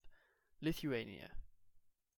2. ^ /ˌlɪθjuˈniə/
LITH-ew-AY-nee-ə;[16] Lithuanian: Lietuva [lʲiətʊˈvɐ]
Lithuania_pronunciation_RP.ogg.mp3